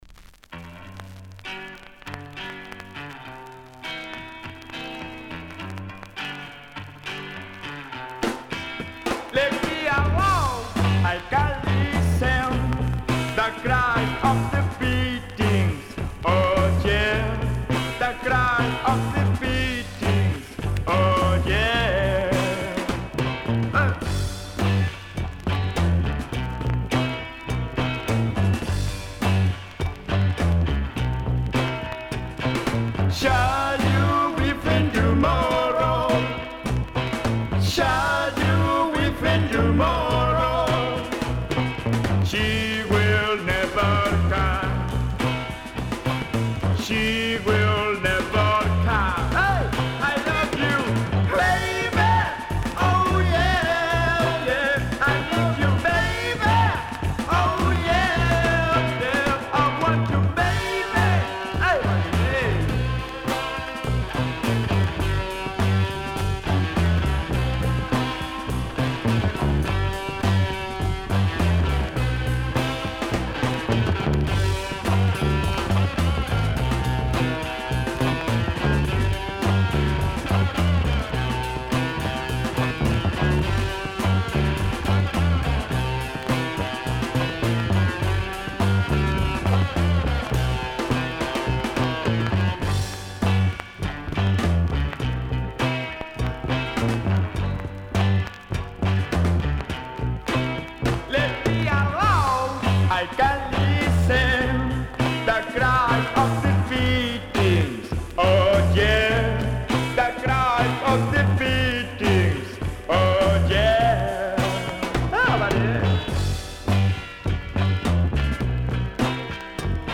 Published February 20, 2010 Garage/Rock Comments